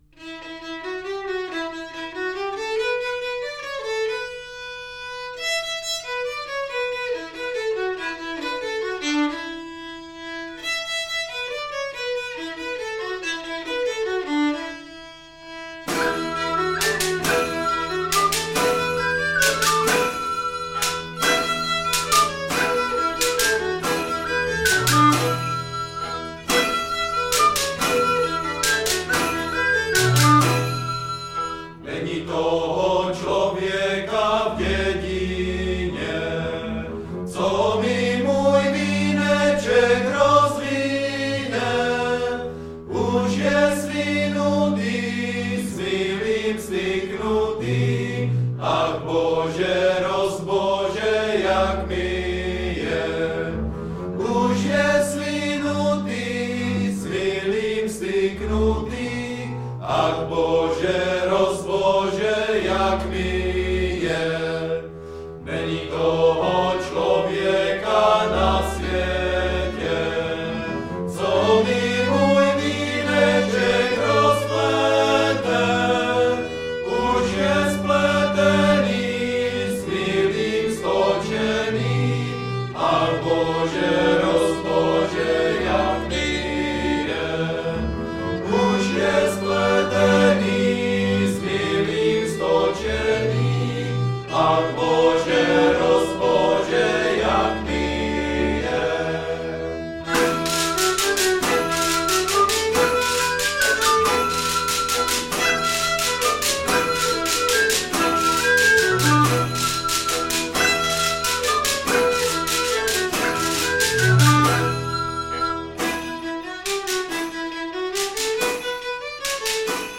Nahrávali jsme ve Spálově v ZUŠce na jaře 2019.